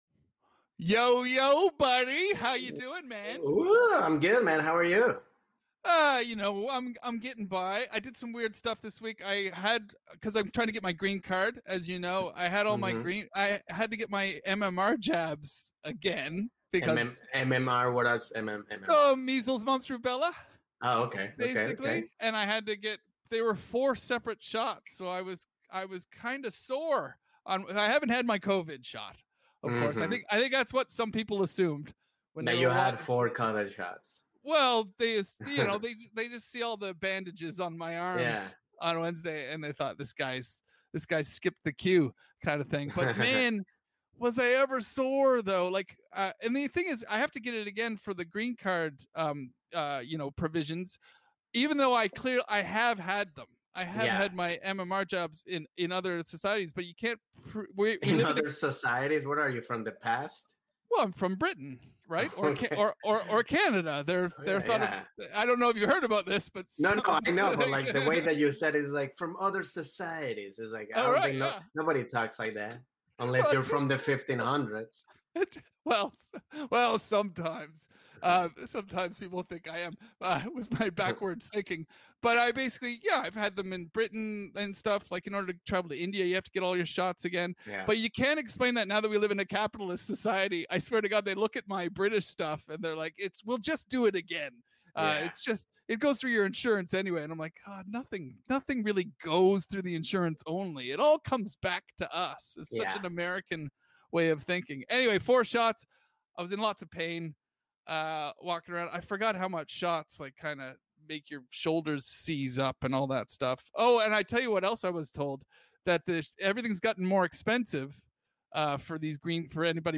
British actress, comedian, and ventriloquist NINA CONTI stops by this week to chat with the guys about puppets, sketchy gigs, and airport massages!